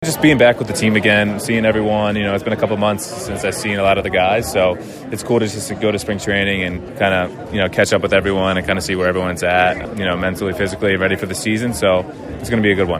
During our interview at the St. Thomas Sports Spectacular back in January, long before the season began- Schneider was already thinking big.